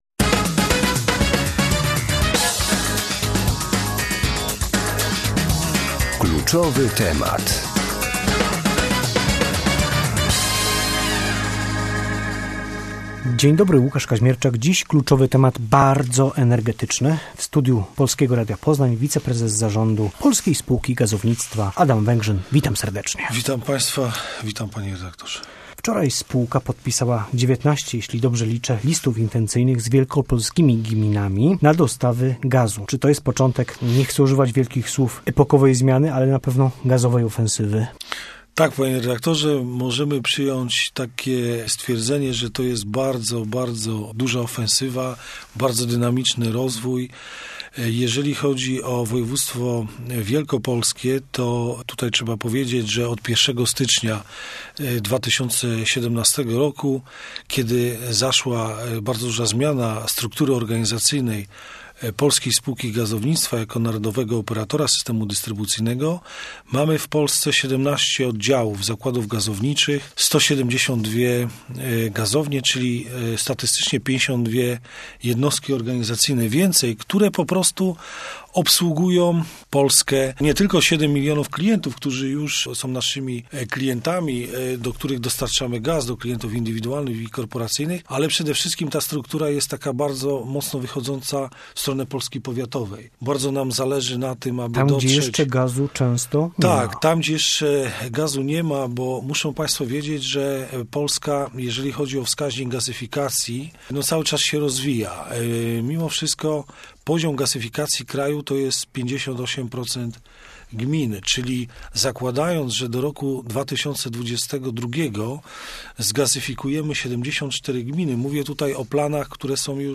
W porannej audycji "Kluczowy temat" o gazie jako narodowym surowcu strategicznym i gazowej ofensywie w Wielkopolsce